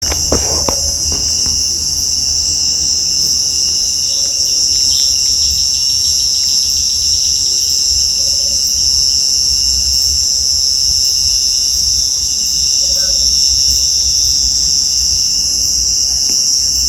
Rufous-capped Motmot (Baryphthengus ruficapillus)
Province / Department: Misiones
Location or protected area: Parque Nacional Iguazú
Condition: Wild
Certainty: Recorded vocal